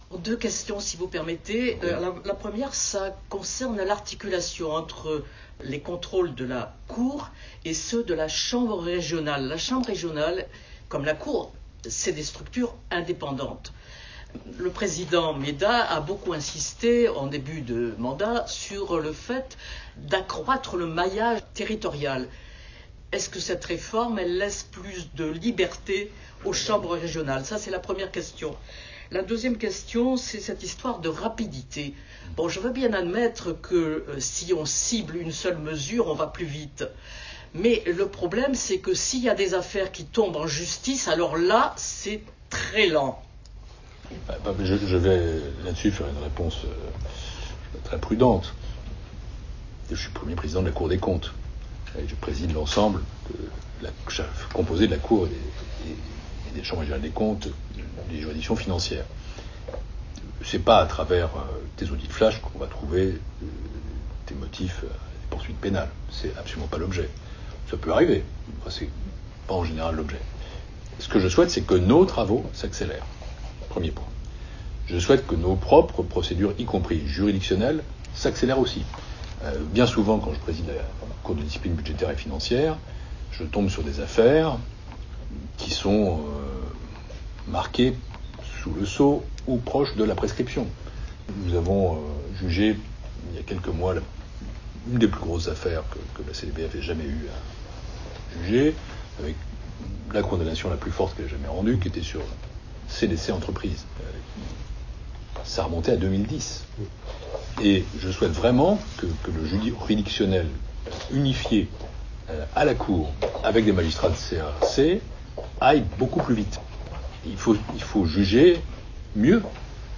Entretien croisé Pierre Moscovici et Nacer Meddah